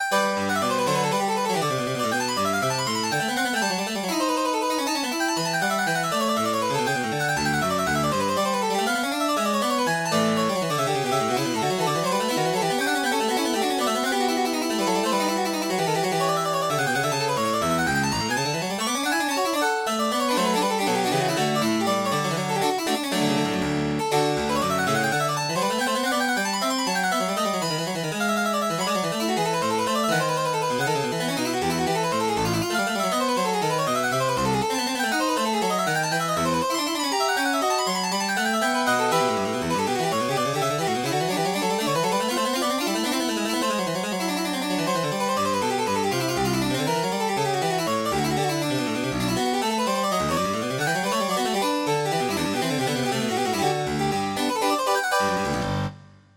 Free Sheet music for Piano
Piano  (View more Intermediate Piano Music)
Classical (View more Classical Piano Music)
18th century    baroque